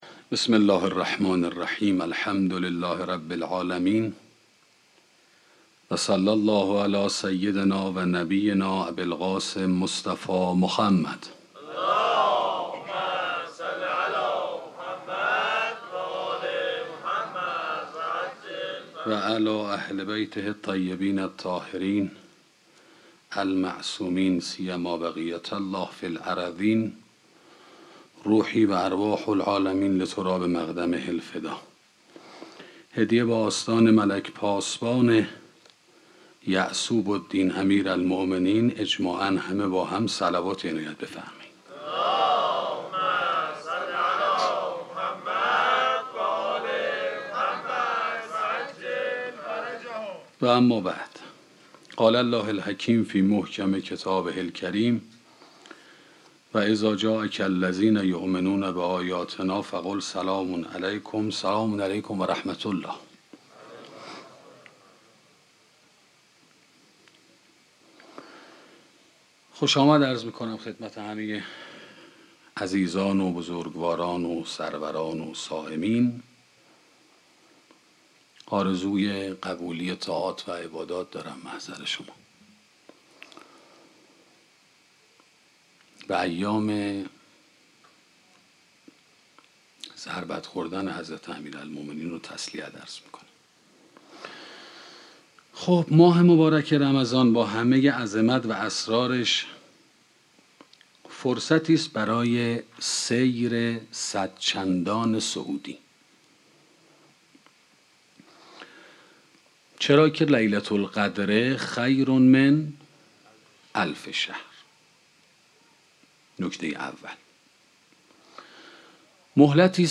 سخنرانی تصویر نظام هستی 1 - موسسه مودت